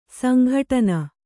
♪ sanghaṭana